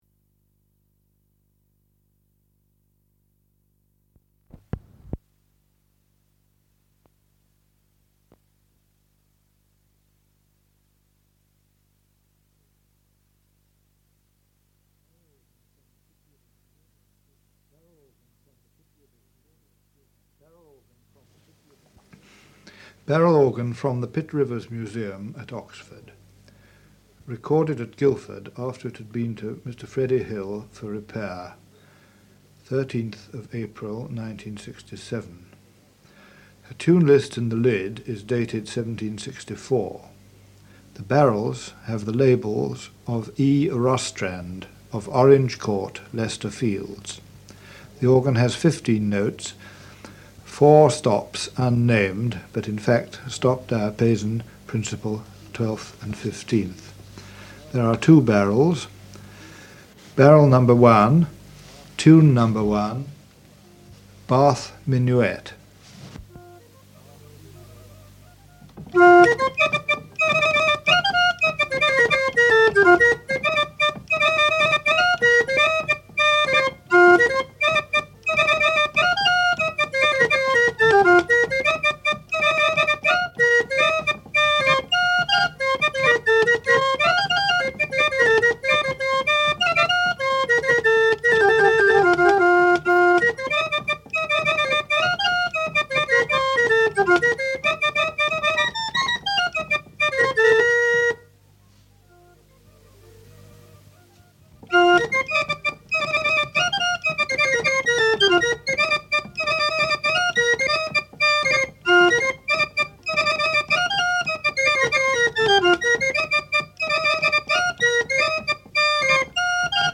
Barrel organs
From the sound collections of the Pitt Rivers Museum, University of Oxford, being one of a small number of recordings of the musical instruments in the institution's collections being played or discussed.